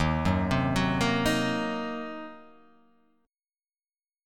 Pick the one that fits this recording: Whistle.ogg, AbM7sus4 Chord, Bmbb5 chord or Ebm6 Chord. Ebm6 Chord